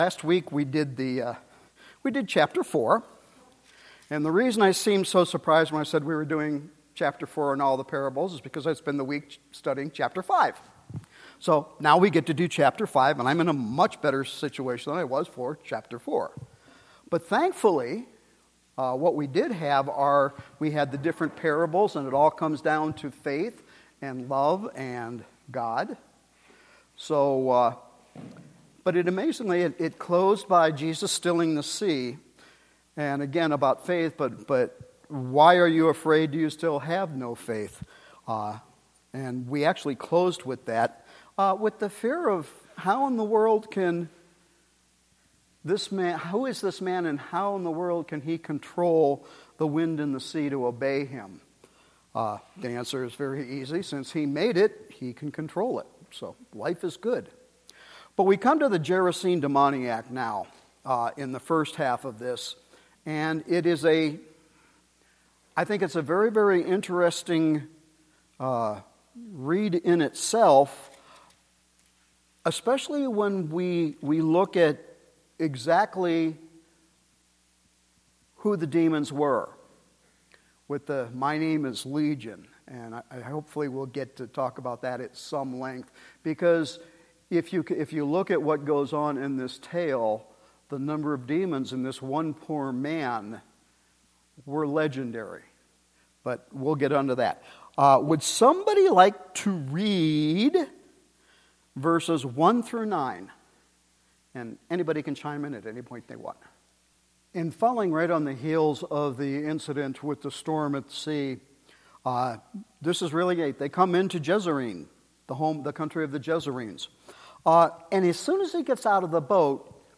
Our new Sunday evening Bible study continues with Mark Chapter 5.